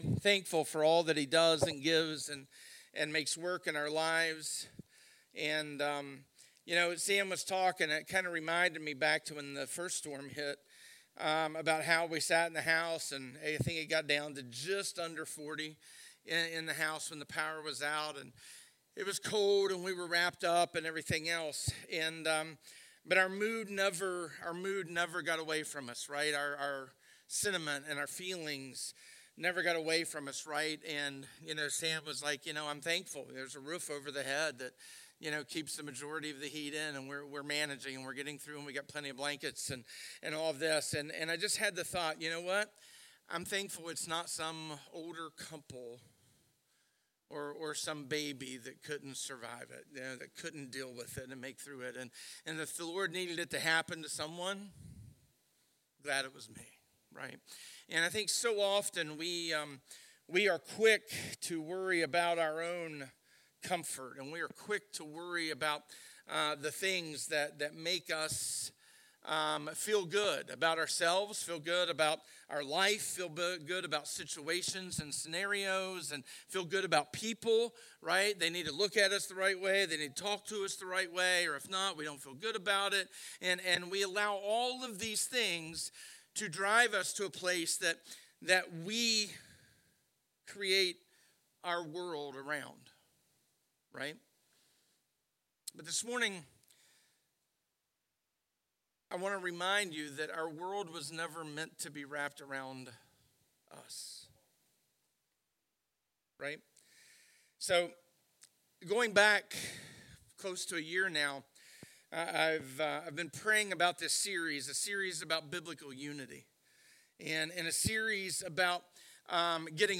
Download - A Narrow Road-A Call To Holiness Part 1 (Winfield, WV) | Podbean